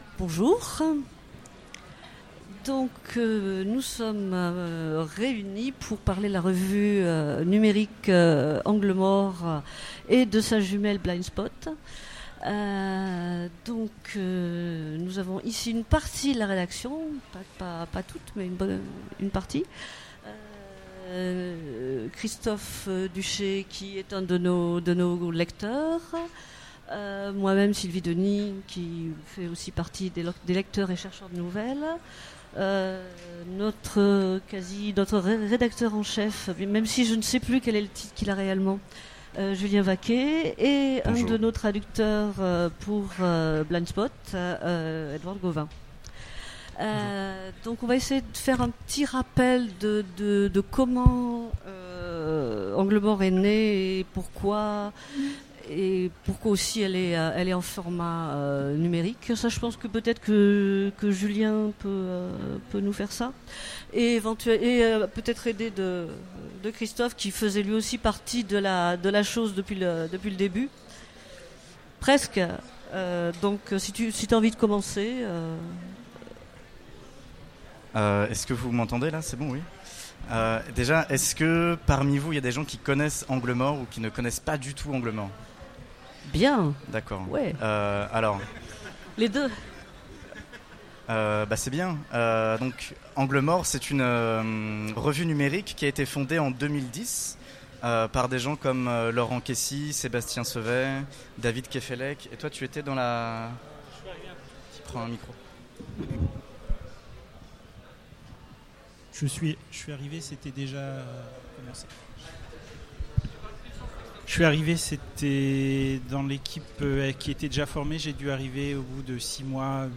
Utopiales 2016 : Conférence La revue Angle Mort Blindspot